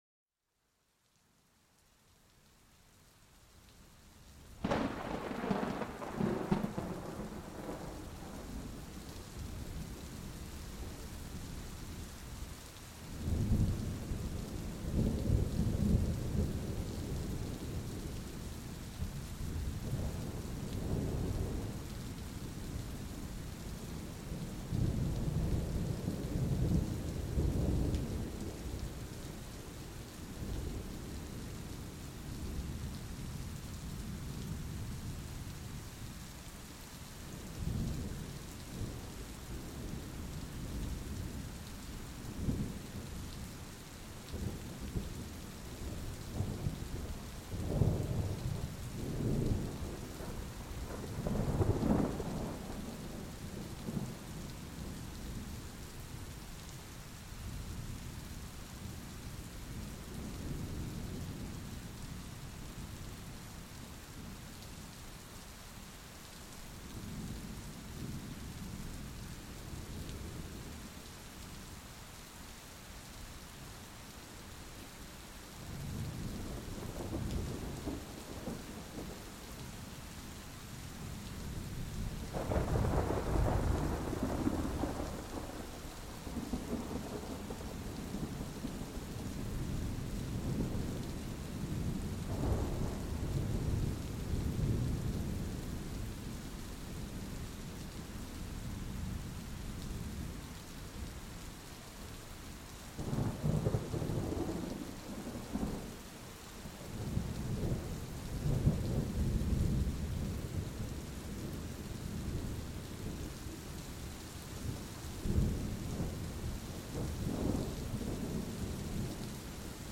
Sumérgete en el corazón de una tormenta lejana, donde los truenos retumbantes se mezclan con el suave golpeteo de la lluvia. Estos sonidos poderosos pero tranquilizadores recrean una atmósfera de calma, perfecta para envolverte y relajarte.